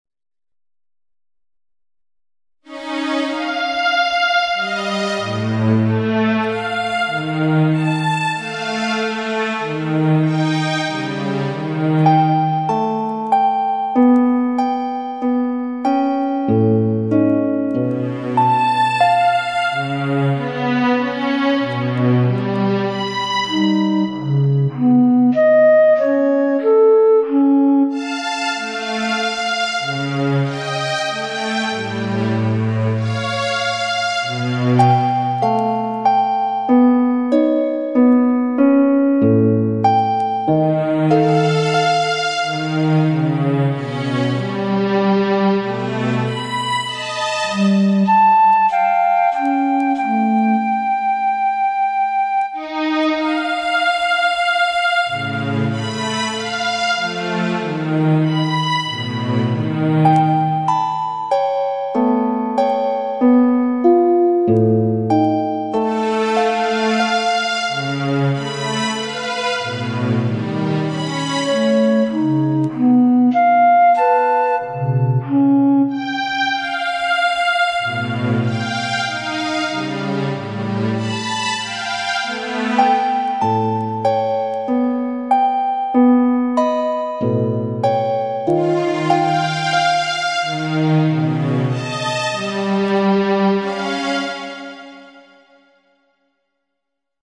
The calcium binding sites are played by harp, the flanking alpha helical regions are played by a string ensemble and the introductory and linking regions are played by flute.